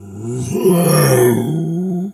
bear_roar_08.wav